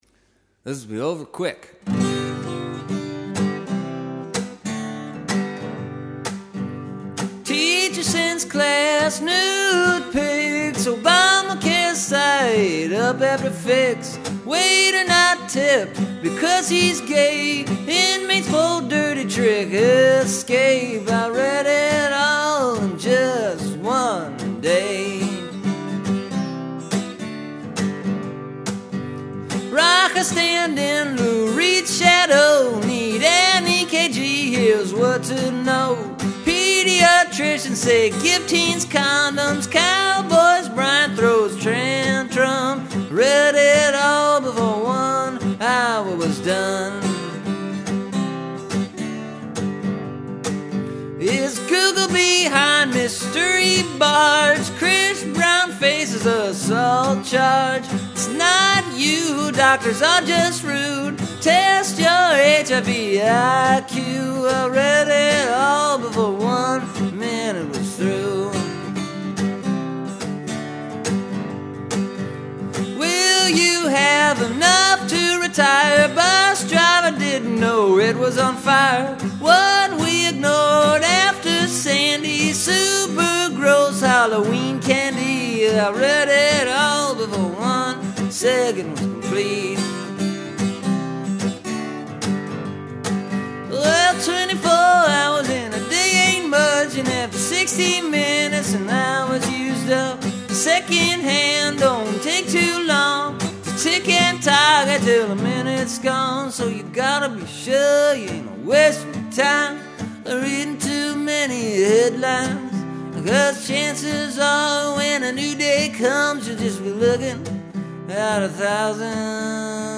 It uses repetition, which is something I’ve found to be useful when writing songs.